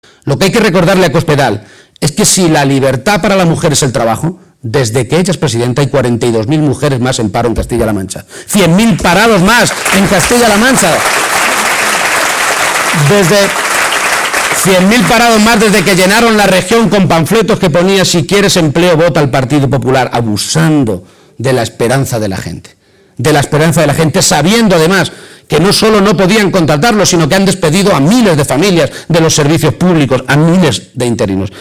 El secretario general del PSOE de Castilla-La Mancha, Emiliano García-Page, ha aprovechado que hoy ofrecía un mitin en la provincia de Cuenca para contestar algunas de las cosas que dijo Cospedal en el acto que celebró el pasado domingo en la capital conquense y señalar que votar al PSOE para que pierda el PP es hacerlo también contra los recortes y la mentira.